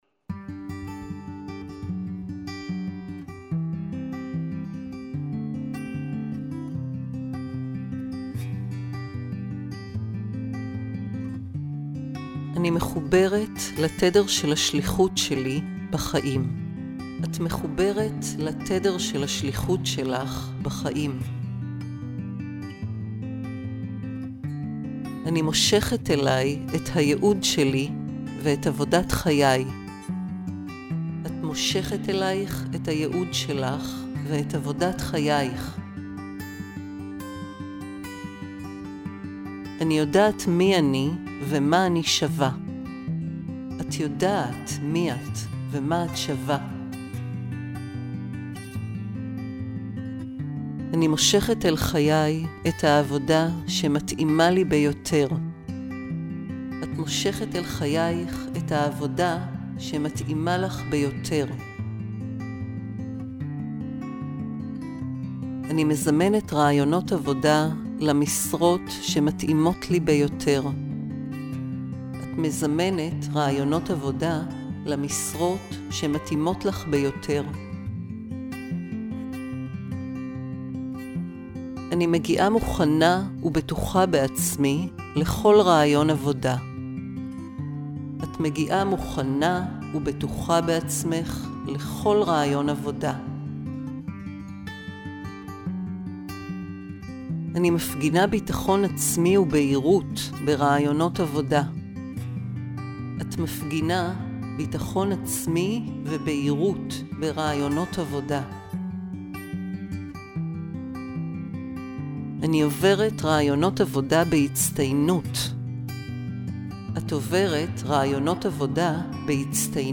דוגמה מהצהרות הגלויות למציאת עבודה לנשים:
לצד הקלטות עם המסרים הסאבלימינלים, תקבלו גם קלטת של ההצהרות עצמן כפי שהוקלטו במקור בשילוב מוזיקה נעימה.